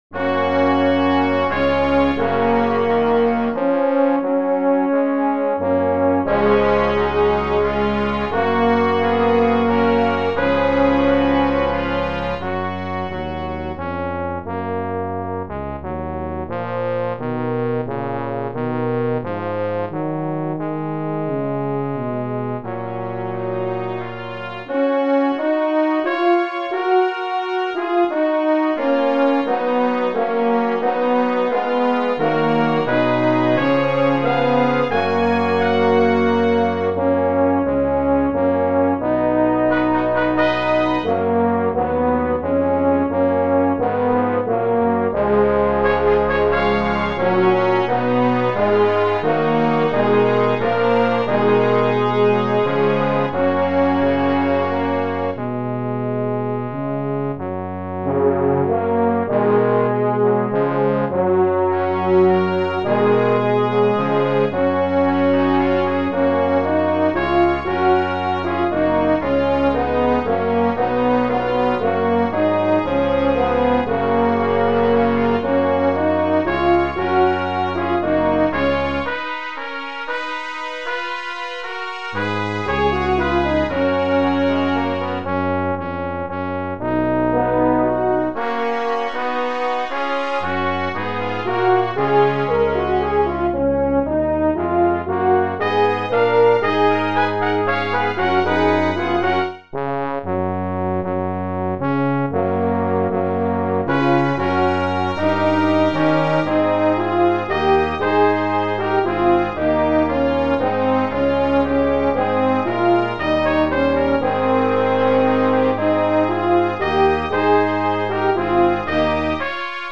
Brass Quintet
17th century German hymn